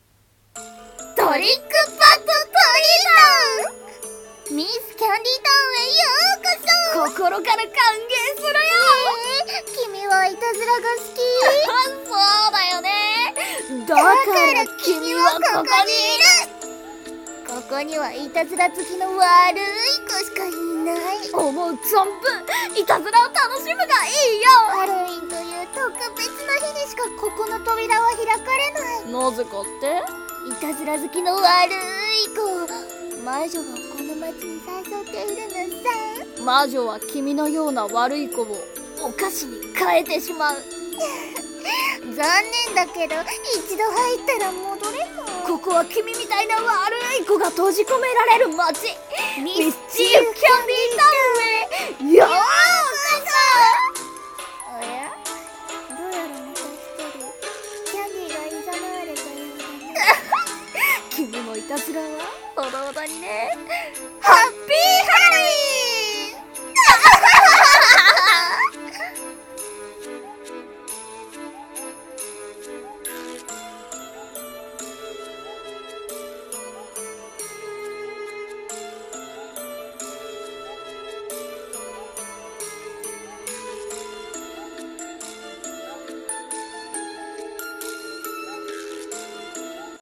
声劇】ミス·キャンディタウン